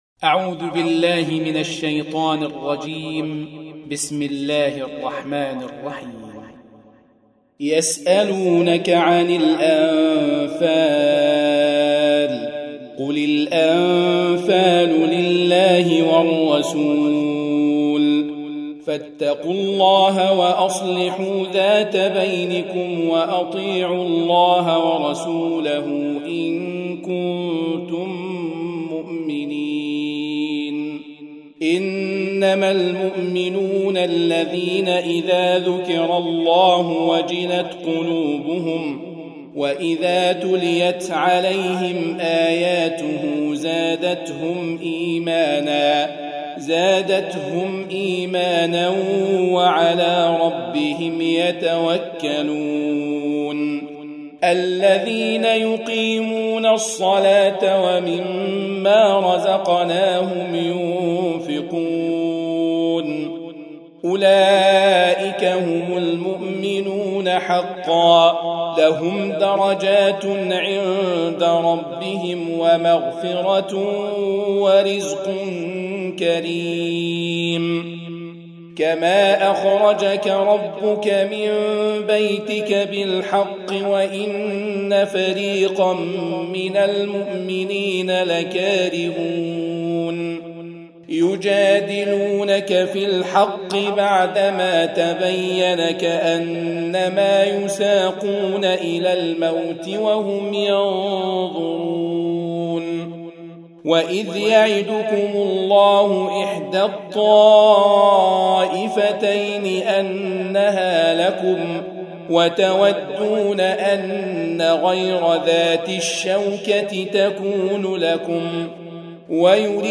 8. Surah Al-Anf�l سورة الأنفال Audio Quran Tarteel Recitation
Surah Repeating تكرار السورة Download Surah حمّل السورة Reciting Murattalah Audio for 8.